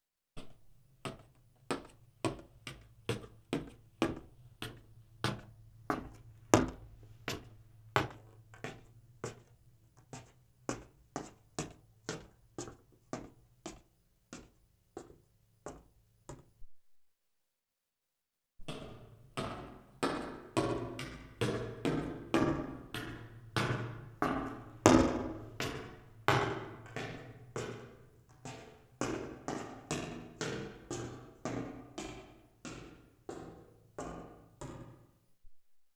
Footsteps recorded in a fixed position, processed to simulate movement in an acoustically dry stairwell, and a highly reverberant one
Footsteps.aiff